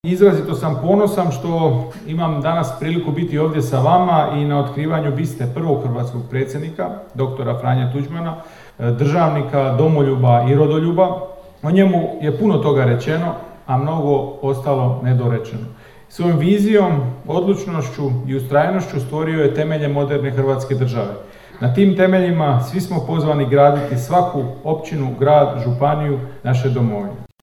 Nakon otkrivanje biste, nazočni su se uputili prema Domu kulture grada Preloga u kojem je održana svečana sjednica Gradskog vijeća. Tu je ministar Malenica u svom govoru između ostalog osvrnuo na otkrivanje biste: